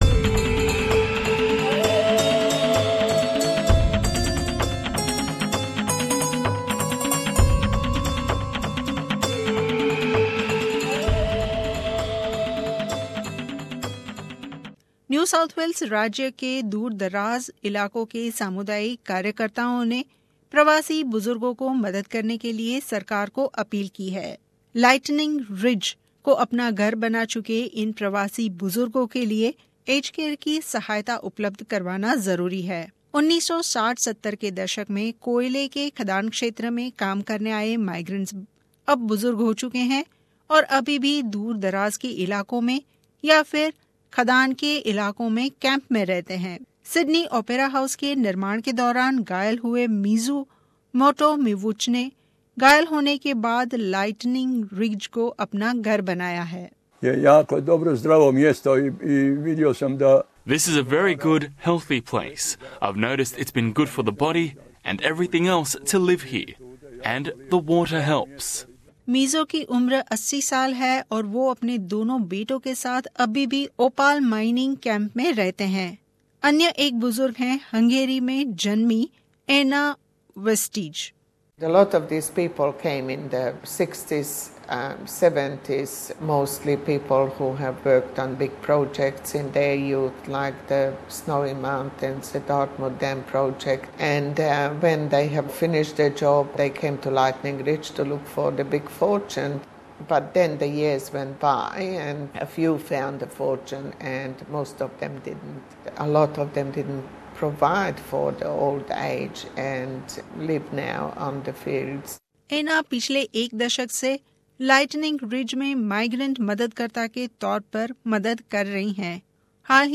न्यू साऊथ वेल्स राज्य के दूर दराज इलाकोंमें रह रहे प्रवासी बुजुर्गो के लिए ज्यादा स्वस्थ्य सुविधा उपलब्ध करवाने के लिए अपील की गई है , प्रस्तुत है रिपोर्ट